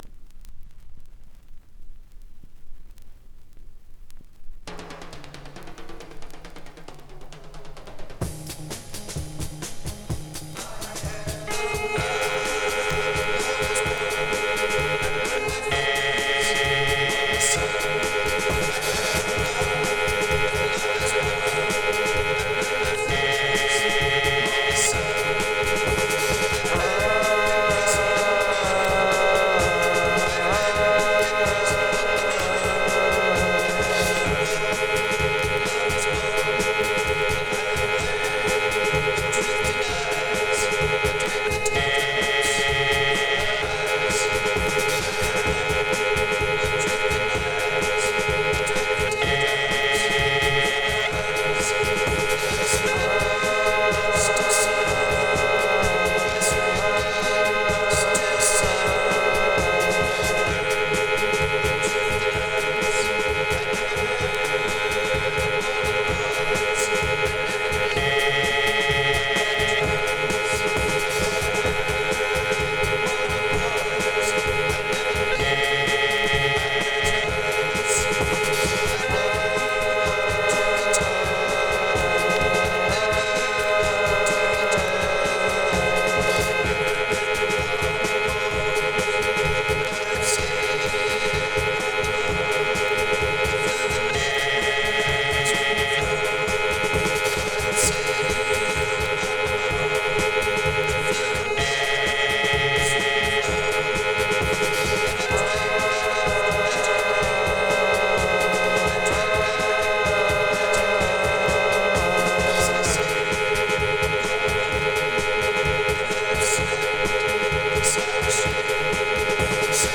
Начало 2й стороны - на сэмпле Цена 16900 руб.